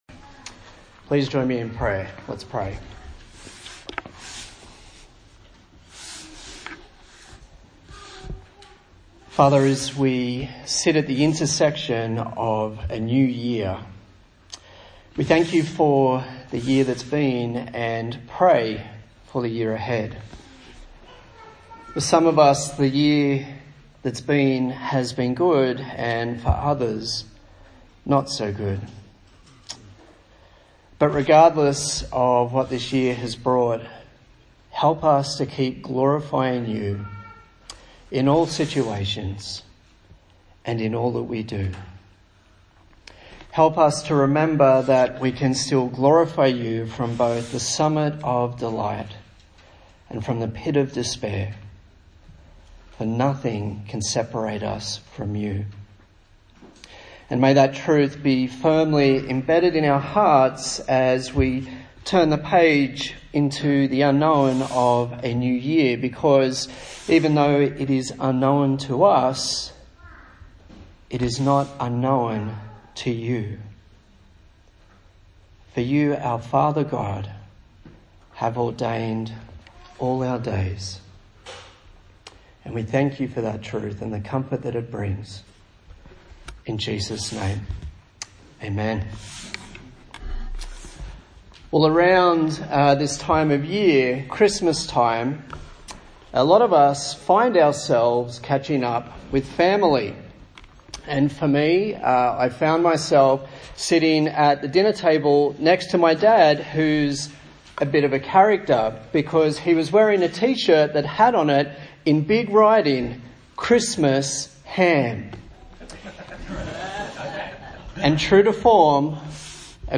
A sermon on the book of 1 Corinthians